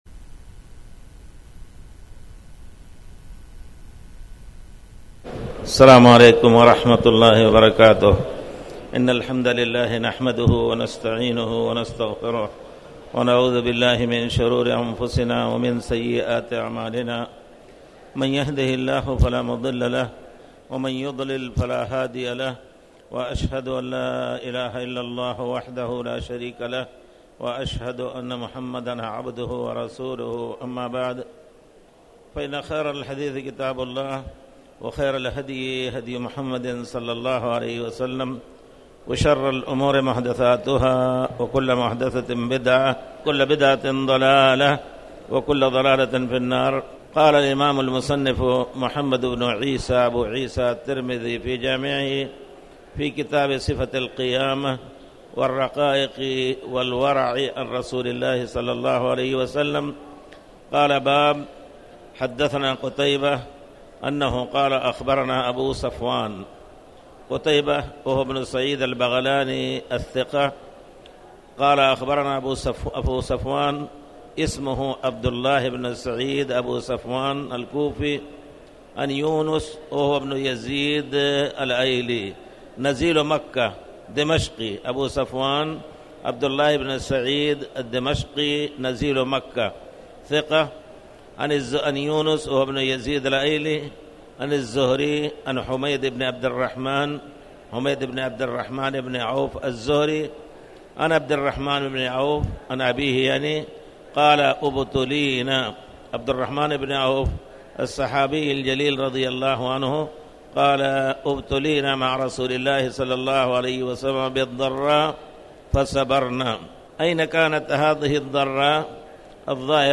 تاريخ النشر ١٥ جمادى الآخرة ١٤٣٩ هـ المكان: المسجد الحرام الشيخ